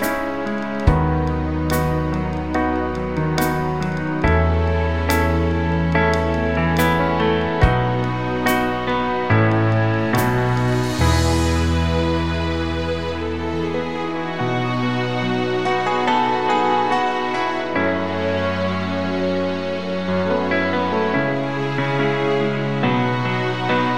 No Lead Guitar Rock 5:00 Buy £1.50